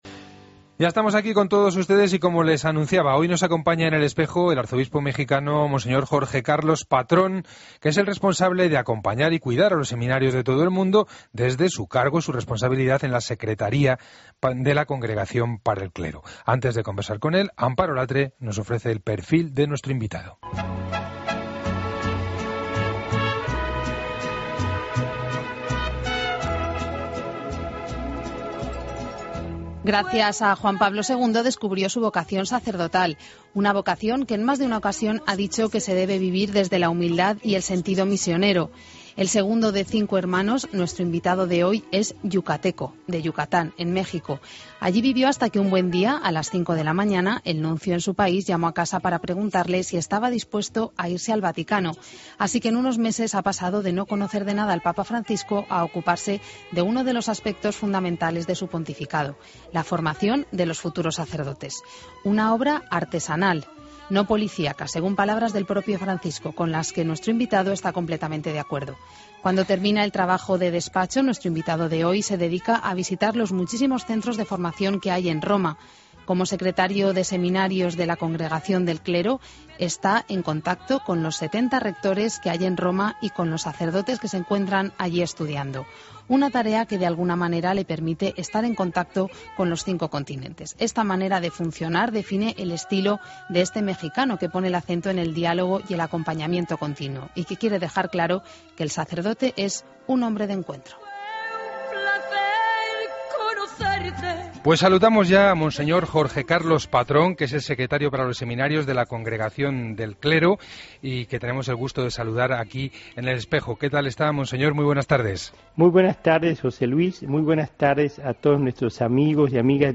AUDIO: Escucha la entrevista completa a monseñor José Carlos Patrón Wong en 'El Espejo' de COPE